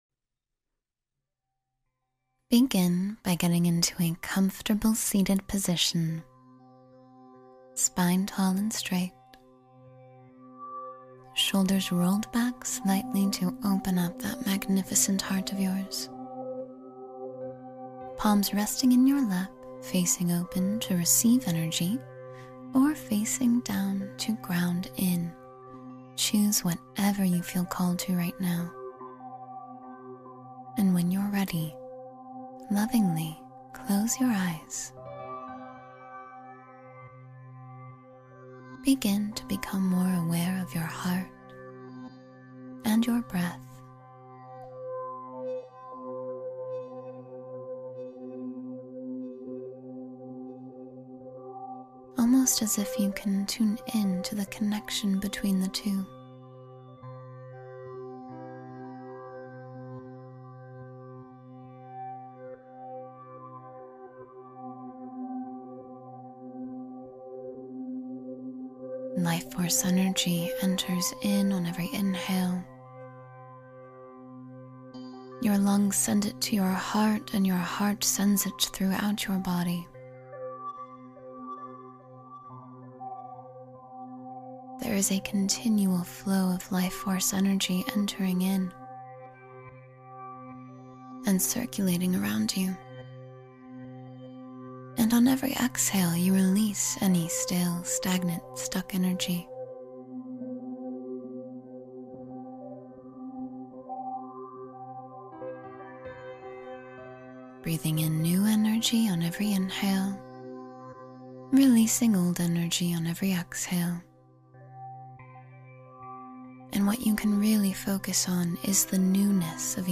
Align with the Energy of Success — Guided Meditation for Prosperity